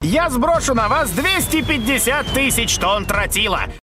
голосовые